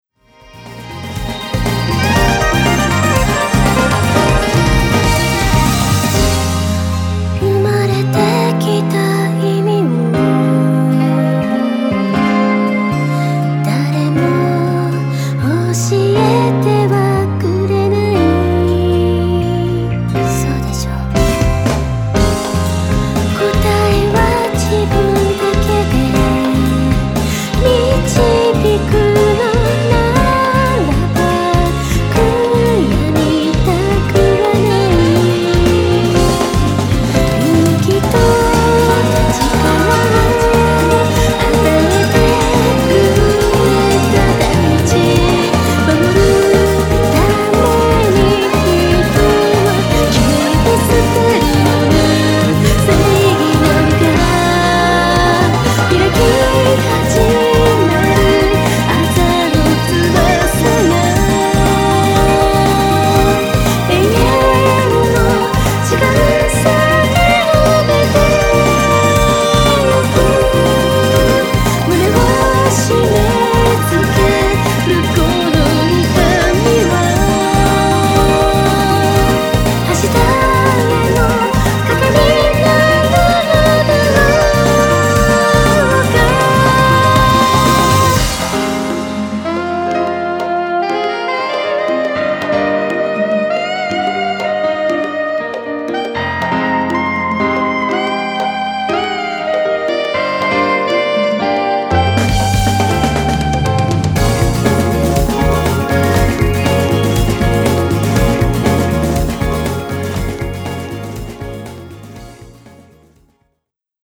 vocal